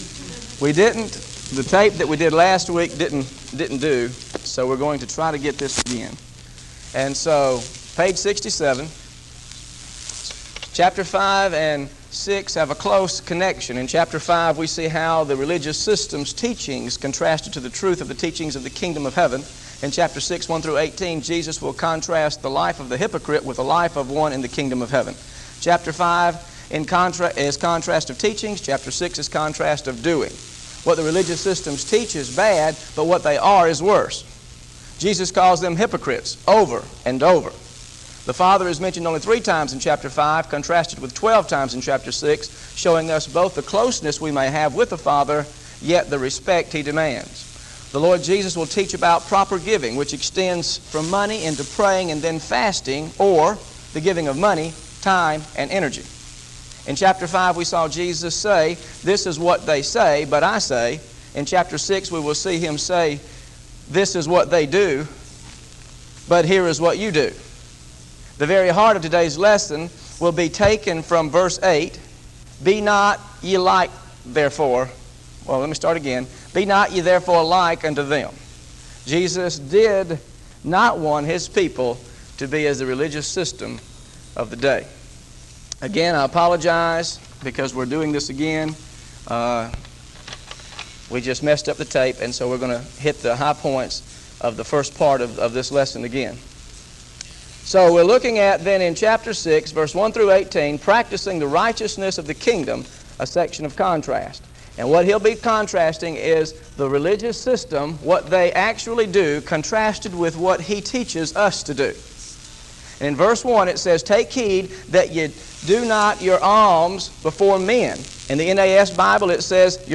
Listen to the Teaching on Matthew 6 1-18 Audio